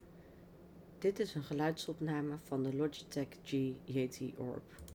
Bij alle drie de geluidsopnames zit ik ongeveer op 40 cm afstand van de microfoon en ik vond dat de microfoon mijn zachte stem goed en duidelijk oppakt.
Wanneer je luistert naar het eerste geluidsfragment zonder de Blue VO!CE, ben ik duidelijk hoorbaar. Er is nauwelijks ruis op de achtergrond hoorbaar en dit is zonder iets aan de instellingen te veranderen.
Logitech-G-Yeti-Orb-Normaal.mp3